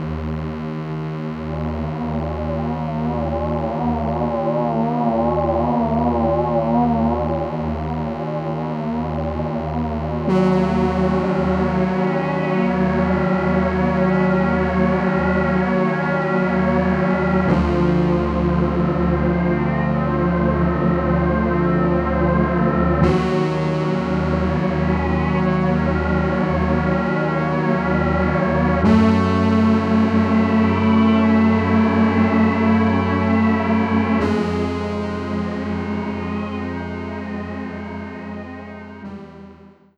1 - Keys Detuned Pad
1_KeysDetunedPad.wav